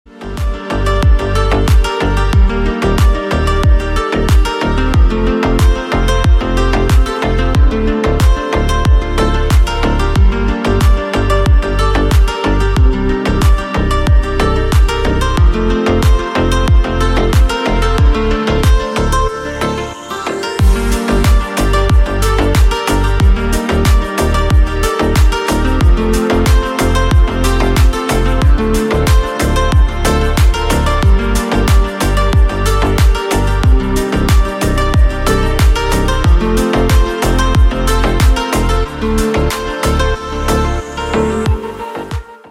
Очень красивая отбивка